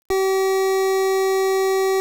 square_pitch.wav